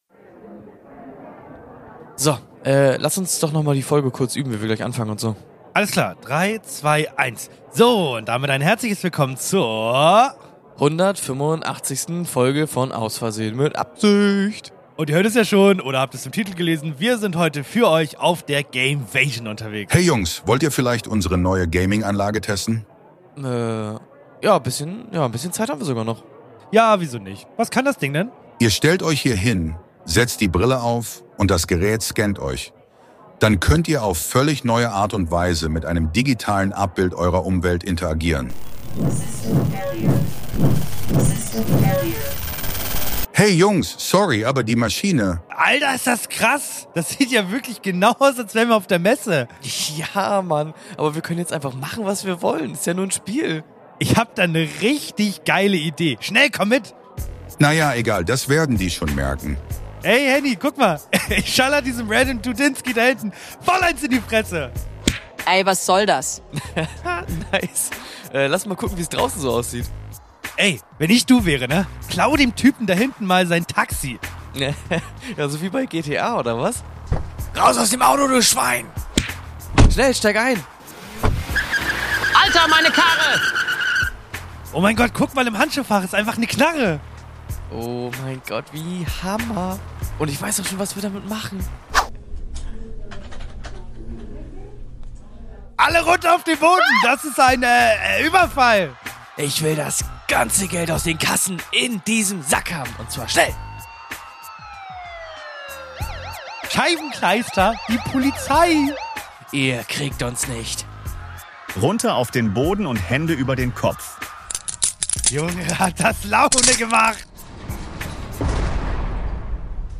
Das Dönermann Gerücht [Live auf der Gamevasion] ~ Aus Versehen mit Absicht Podcast
Ihr durftet uns bestaunen und mit Tomaten bewerfen, während wir live für euch unsere Folge auf der Gamevasion aufgenommen haben!